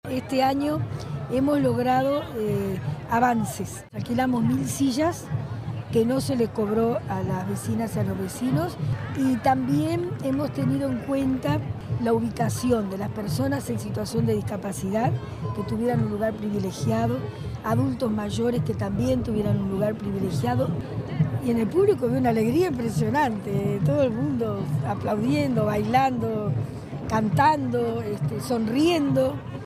sonia_misirian_alcaldesa_ciudad_de_la_costa_1.mp3